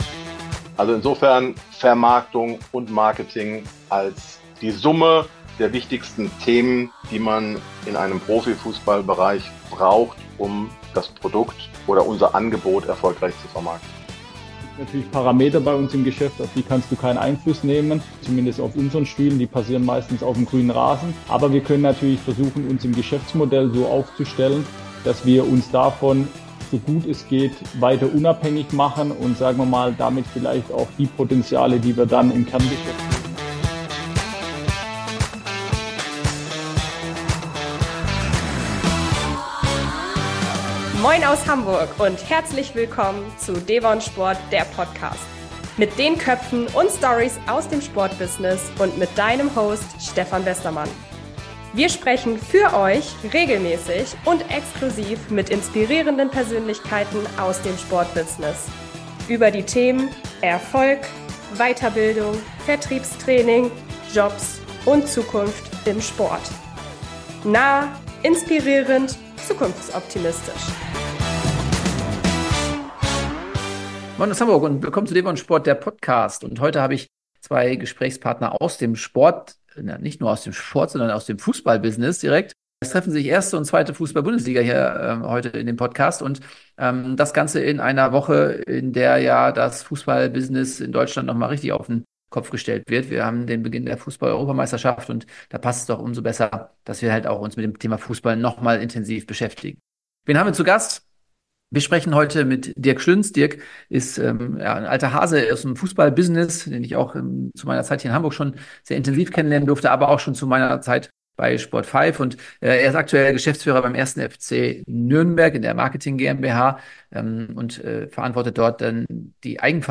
Und heute geht es rund um das Thema Eigenvermarktung im Profifußball. 2 Experten haben diese Umstellung mit Bravour gemeistert und teilen mit uns ihre Erfahrungen und Learnings!